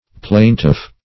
Plaintiff \Plain"tiff\, n. [F. plaintif making complaint,